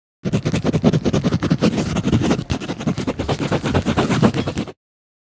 writing.wav